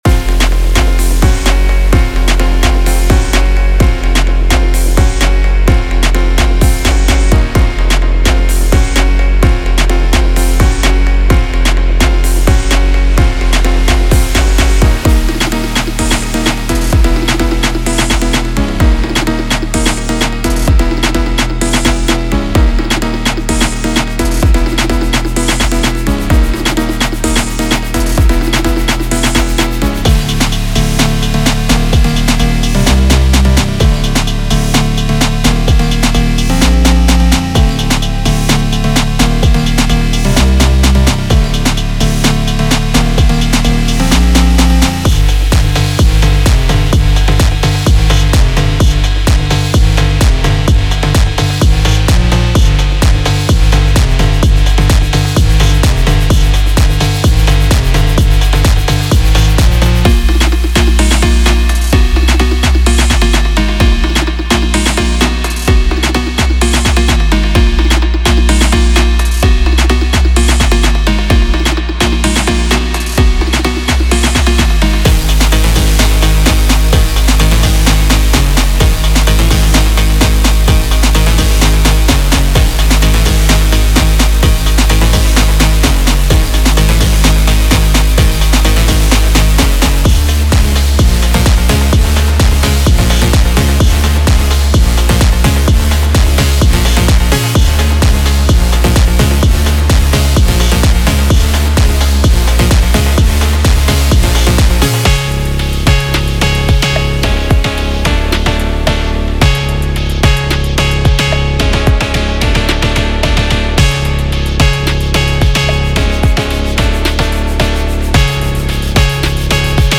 • 20 Bass Loops: Deep, rumbling basslines designed to add weight and intensity to your tracks, perfect for creating a solid foundation.
• 30 Drum Loops: A dynamic selection of drum loops featuring hard-hitting kicks, crisp snares, and intricate hi-hat patterns to craft the quintessential Phonk groove.
• 13 FX Loops: Atmospheric and tension-building FX loops that add an extra layer of depth and intrigue, elevating your sound to new heights.
• 25 Synth Loops: Mesmerizing synth loops that provide haunting melodies and lush textures, setting the perfect mood for your Phonk productions.
• High-Quality Audio: Professionally recorded and mastered for top-notch sound quality, ensuring your tracks are always production-ready.
• Genre-Specific: Captures the authentic feel of Phonk with a mix of vintage and contemporary elements, making it versatile for various hip-hop subgenres.